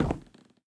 Minecraft Version Minecraft Version snapshot Latest Release | Latest Snapshot snapshot / assets / minecraft / sounds / block / chiseled_bookshelf / step5.ogg Compare With Compare With Latest Release | Latest Snapshot
step5.ogg